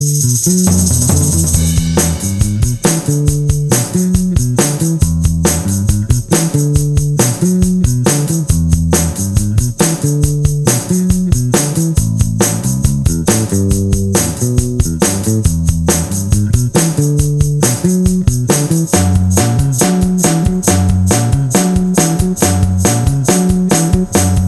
No Guitars Pop (1960s) 2:51 Buy £1.50